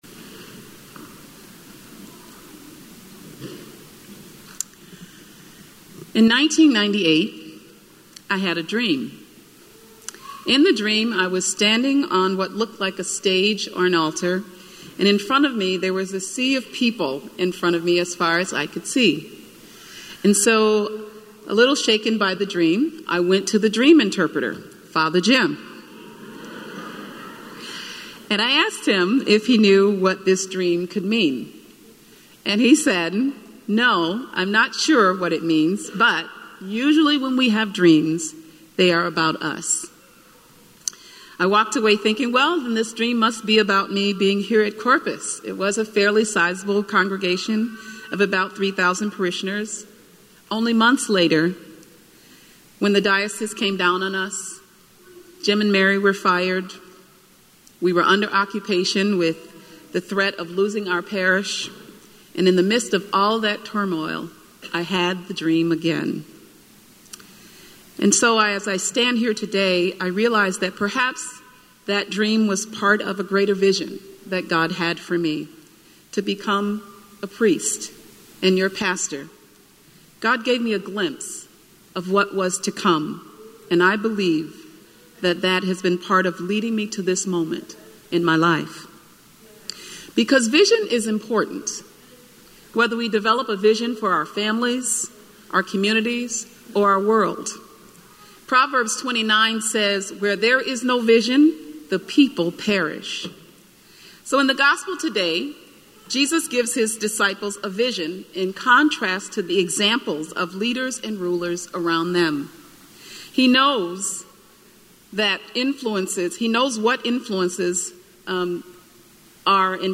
Spiritus Christi Mass October 21st, 2018